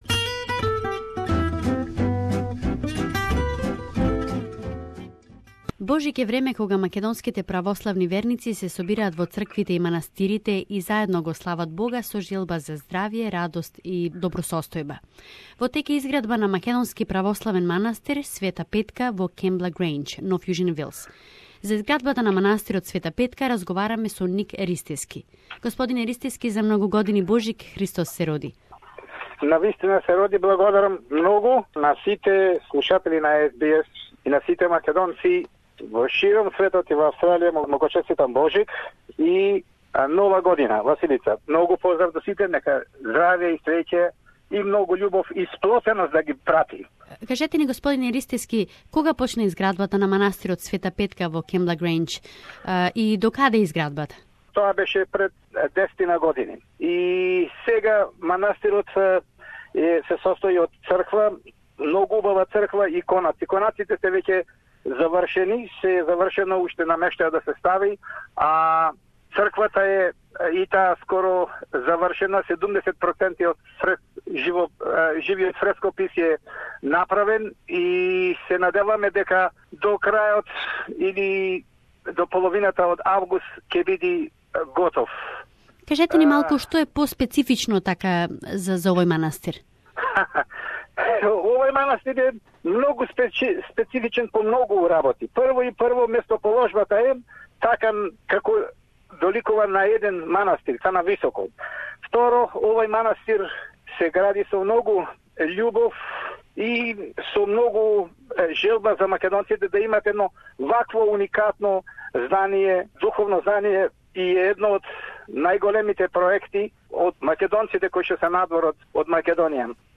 Monastery St Petka in Kembla Grange is about to be finished and believers are ready for the humanitarian concert. Interview